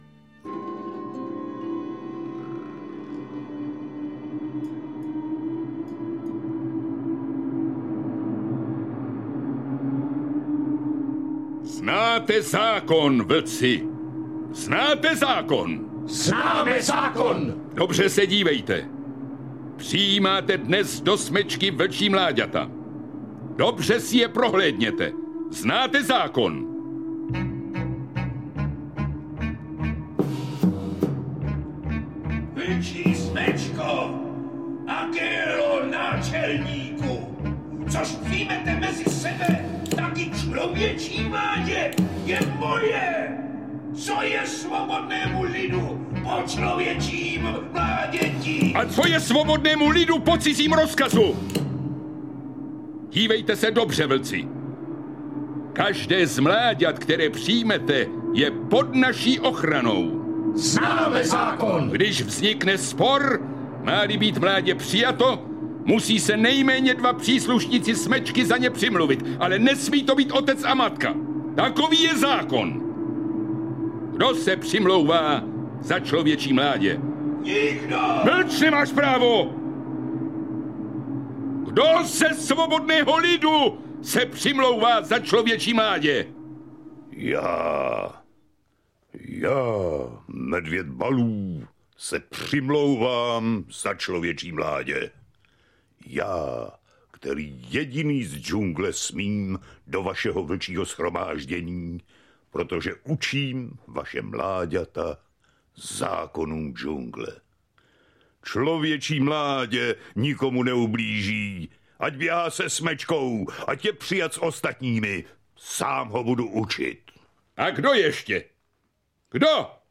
Audiokniha Dárek pro milovníky mluveného slova - obsahuje výběr zvukových dramatizací oblíbených dobrodružných příběhů pro malé i velké posluchače.